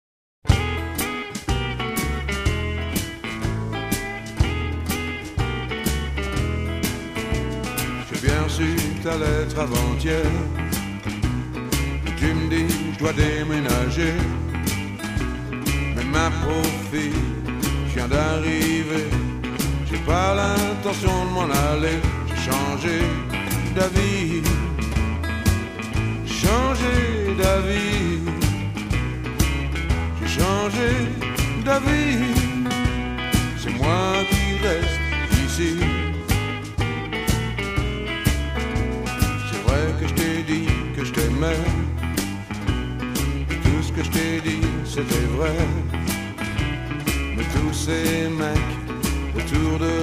chant, harmonica, slide guitar, guitare
f-basse, contrebasse
batterie, percussions
piano, accordeon
steel guitar
frottoir, planche à laver